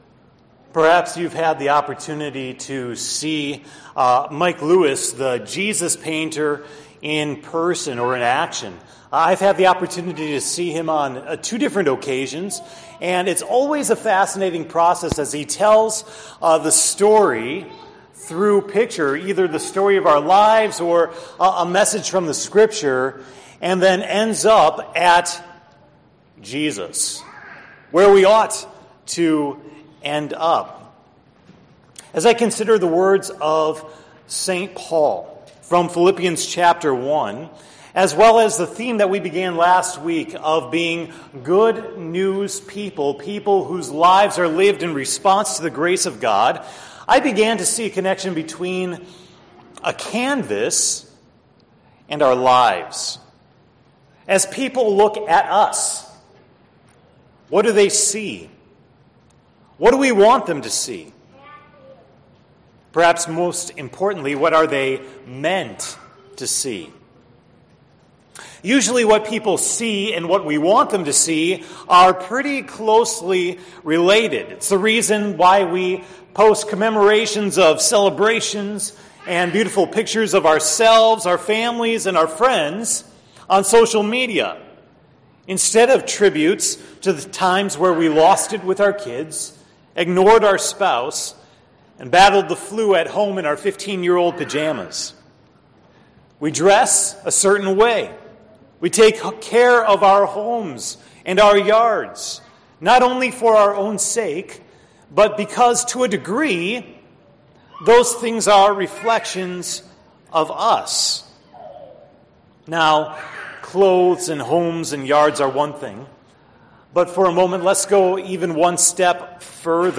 The sermon for September 24, 2017 at Hope Text: Philippians 1:12-14, 19-30